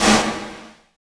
27 SN VERB-L.wav